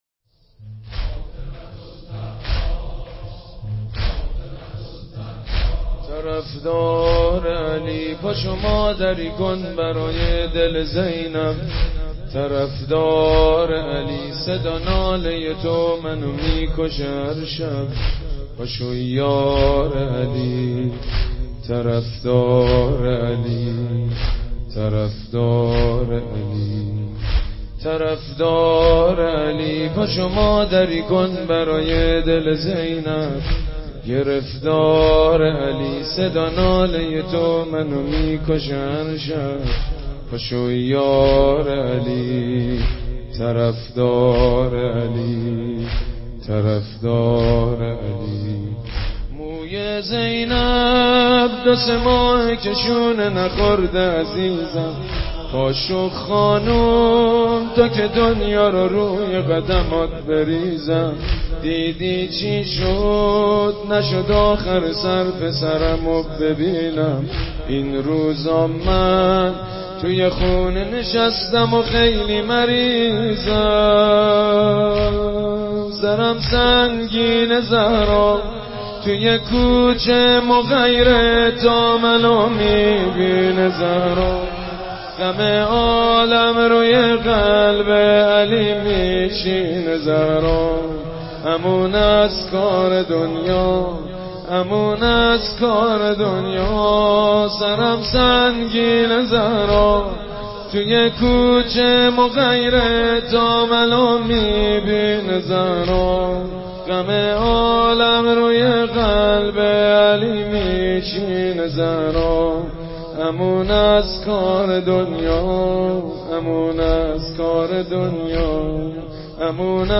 عنوان : سینه زنی ویژۀ ایام فاطمیه